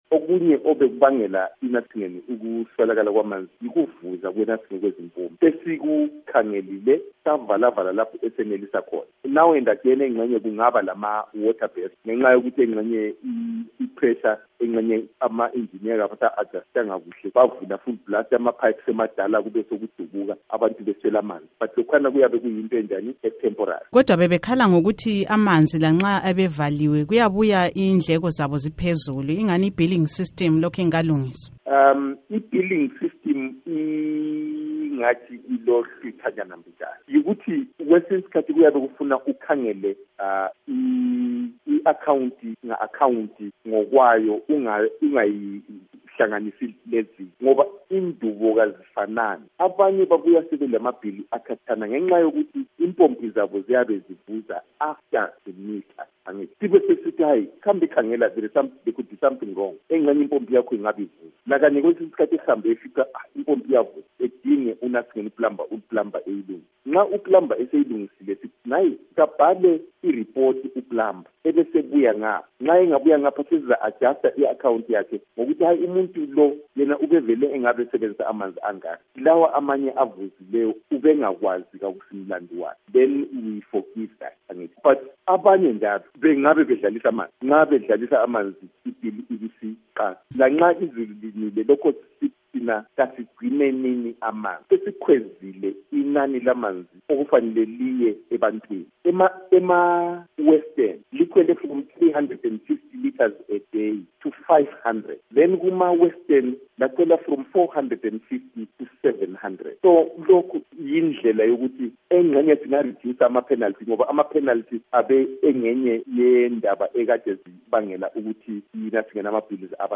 Ingxoxo loMnu. Martin Moyo uMayor wakoBulawayo